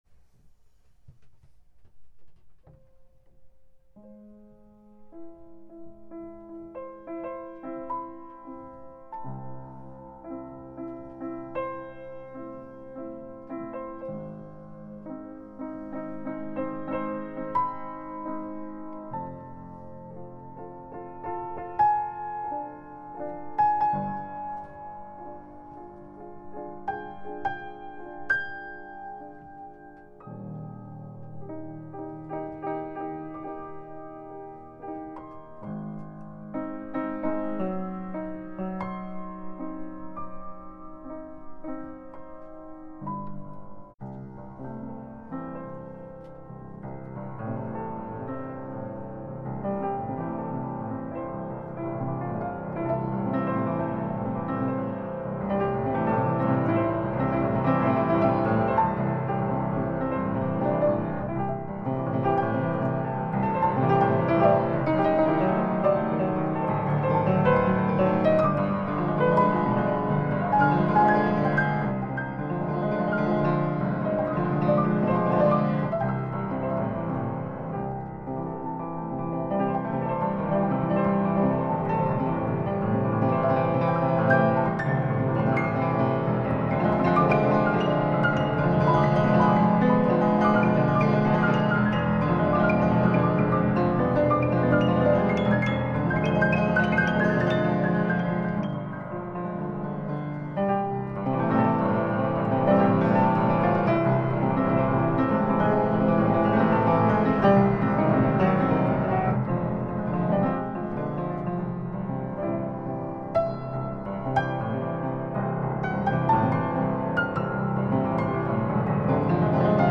Музыкальный подарок
- три фортепианные пьесы, сочиненные мною единомоментно - в процессе игры, или сыгранные в процессе сочинения, то есть, сразу.